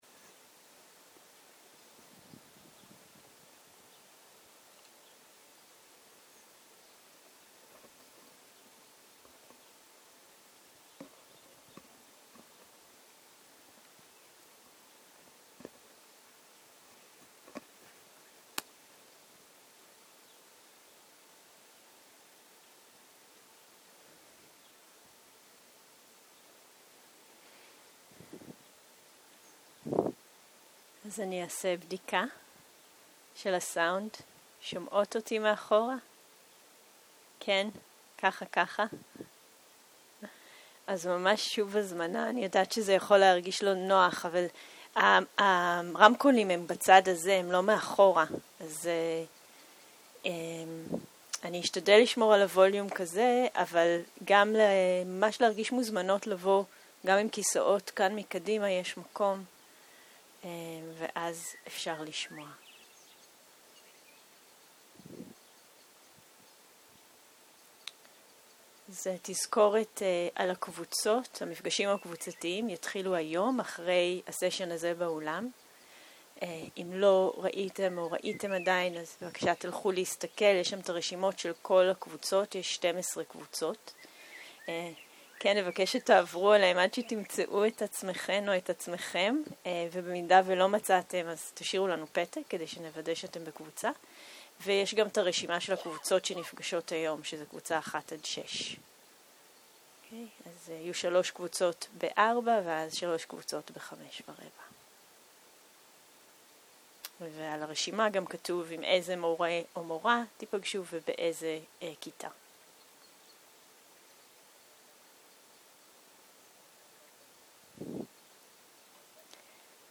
צהריים - מדיטציה מונחית - מטא לעצמי, לדמות הקלה ולקבוצה
סוג ההקלטה: מדיטציה מונחית